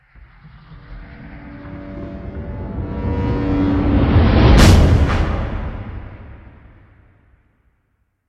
Нагоняющие страх звуки для монтажа видео и просто испугаться слушать онлайн и скачать бесплатно.